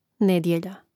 nèdjelja nedjelja